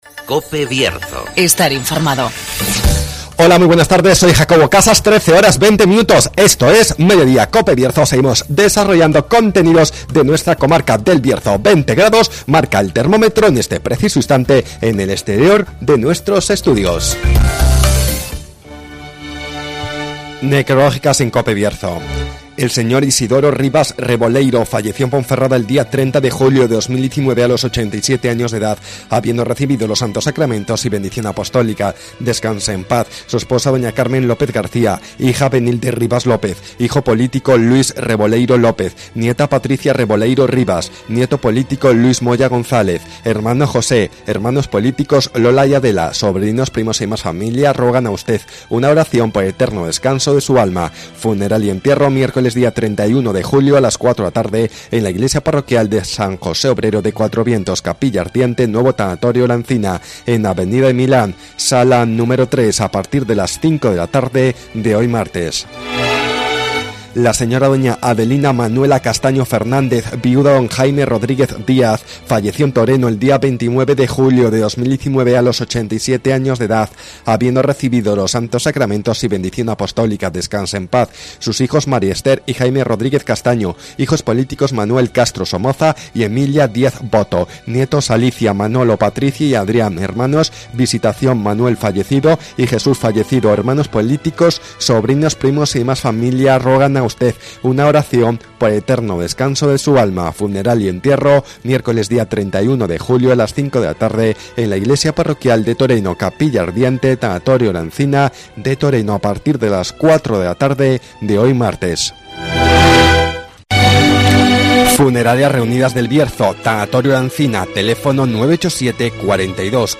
AUDIO: Repasamos la actualidad y realidad del Bierzo. Espacio comarcal de actualidad, entrevistas y entretenimiento.